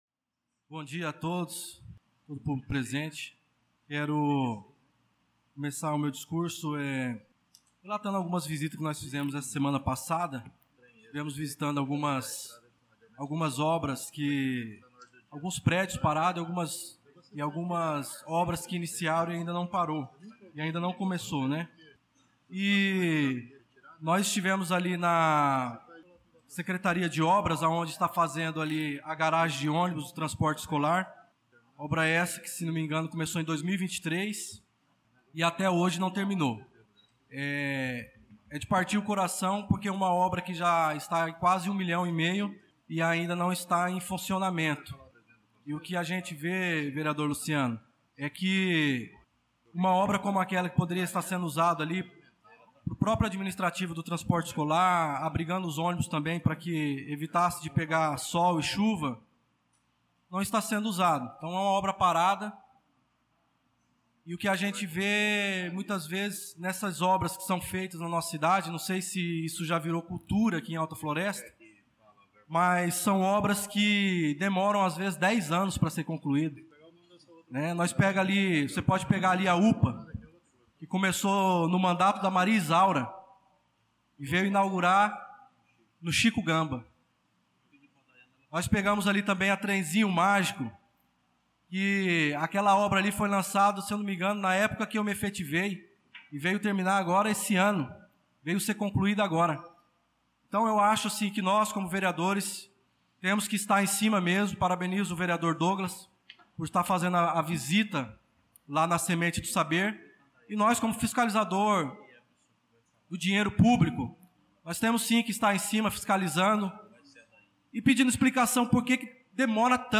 Pronunciamento do vereador Darlan Carvalho na Sessão Ordinária do dia 06/03/2025